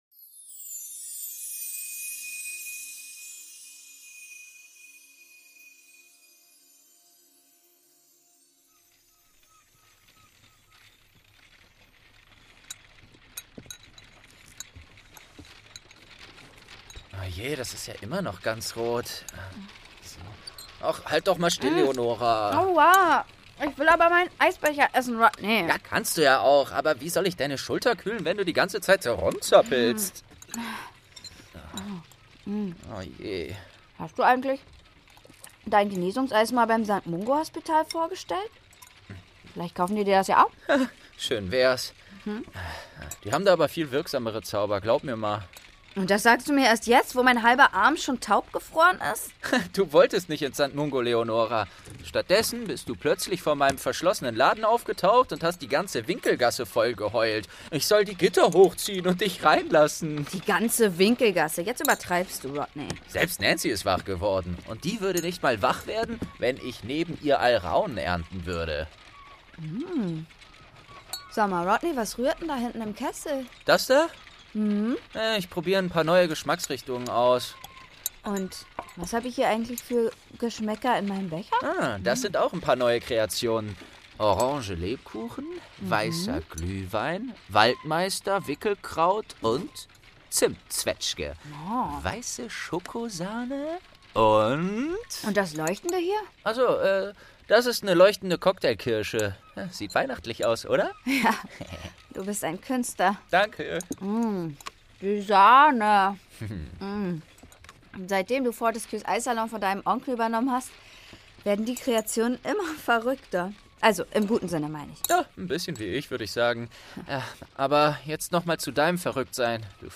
Türchen | Eis mit Rodney - Eberkopf Adventskalender ~ Geschichten aus dem Eberkopf - Ein Harry Potter Hörspiel-Podcast Podcast